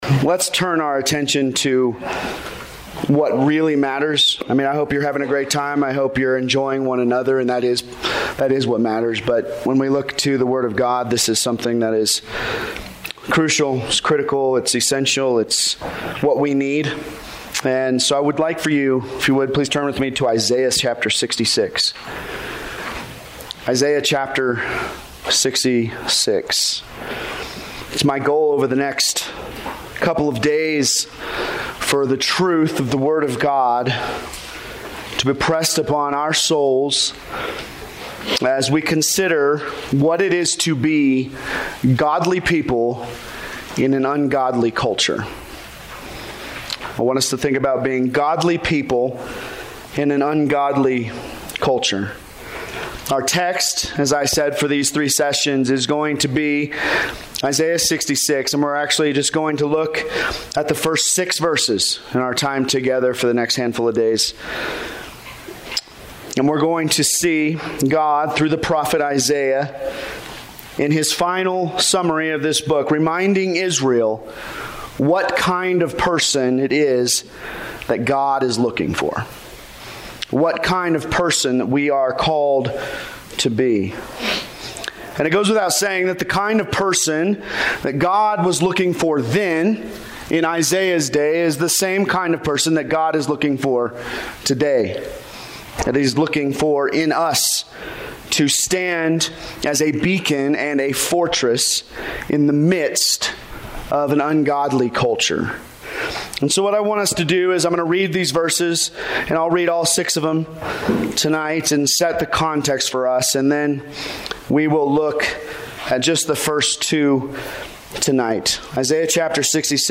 College/Roots Roots Winter Retreat - 2022 Audio Series List Next ▶ Current 1.